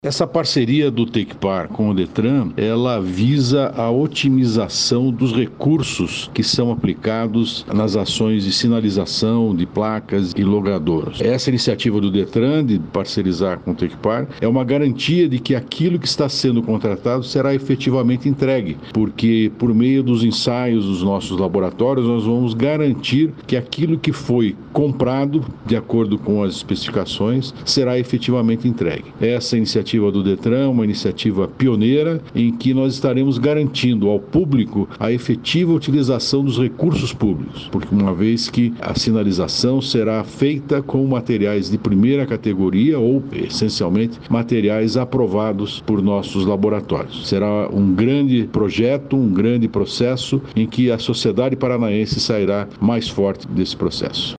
Sonora do diretor-presidente do Tecpar, Celso Kloss, sobre a parceria com o Detran para vistoria de itens de segurança viária nos municípios